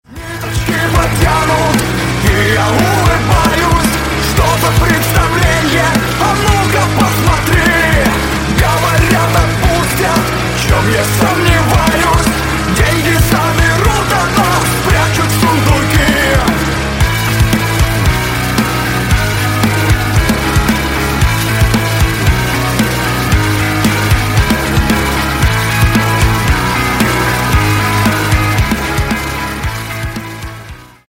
Громкие Рингтоны С Басами
Рок Металл